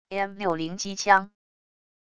M60机枪wav音频